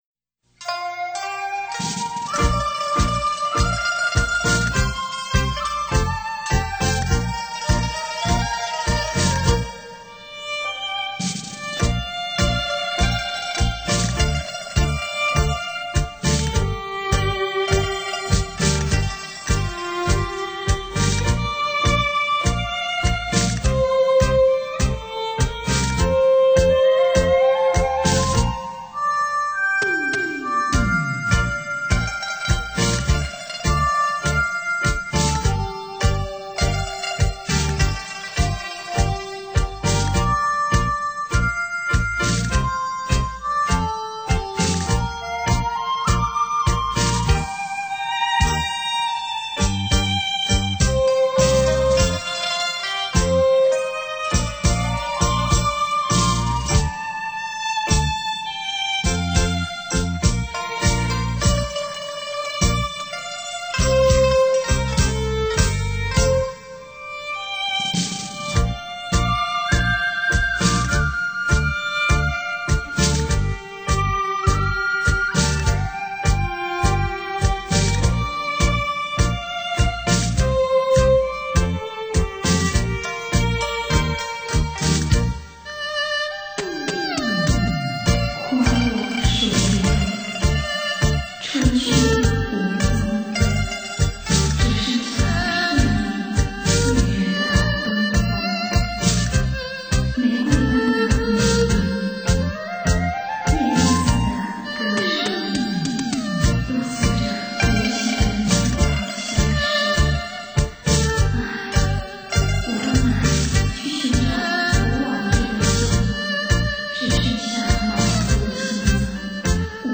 扬琴,琵琶,笛,箫,笙等配合西乐合奏出雅俗共赏的音乐~